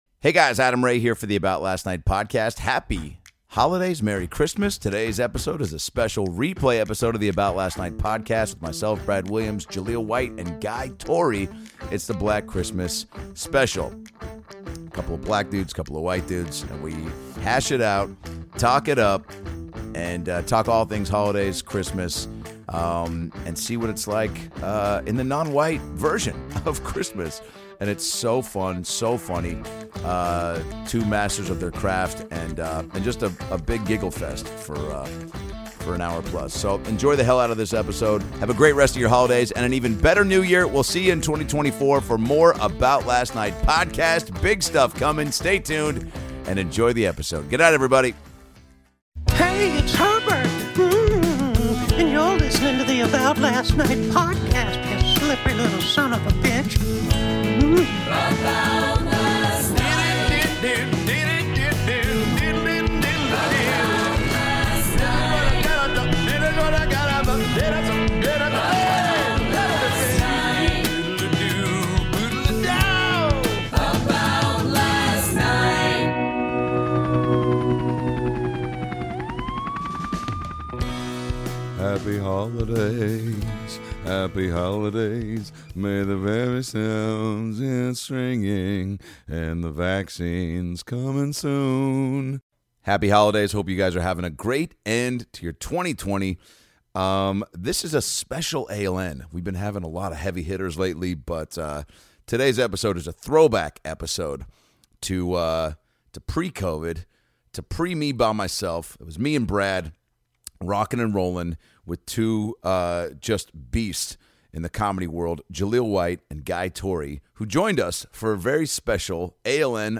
Jaleel White & comedian Guy Torry break down BLACK XMAS in this ALN CLASSIC! Enjoy this special throwback episode when Brad Williams & Adam Ray got educated by two of the best in the biz!